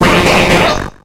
Cri de M. Mime dans Pokémon X et Y.